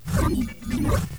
Crash sound was way too loud, Portal also felt slightly annoying - crash: -7dB - portal: -3dB 2024-01-07 21:47:26 +01:00 103 KiB Raw History